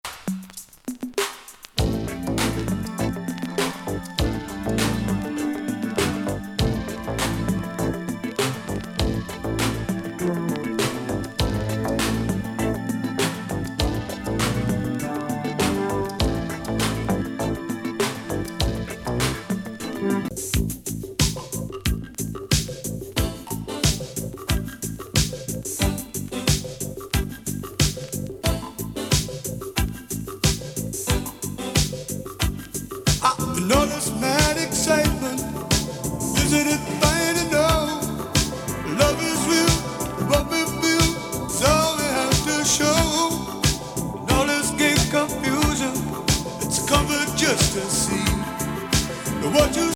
リンドラム＋シンセに酒焼けボーカル